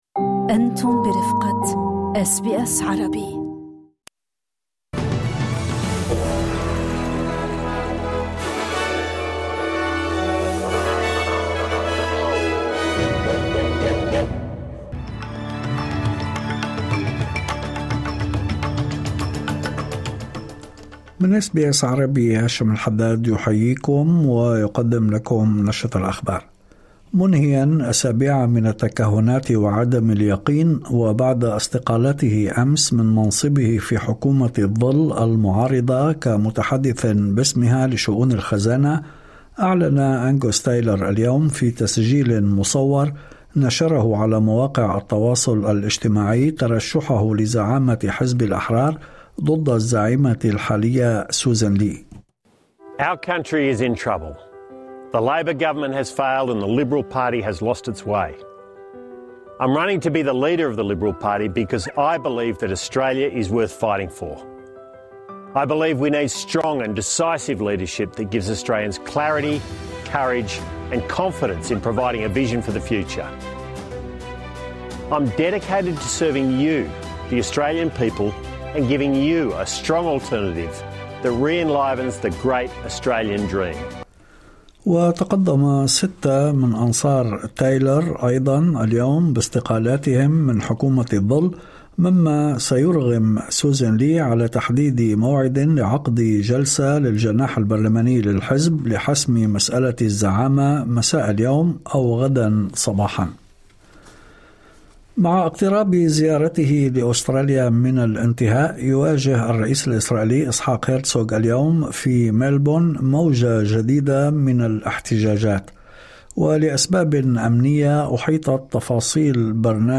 نشرة أخبار الظهيرة 12/02/2026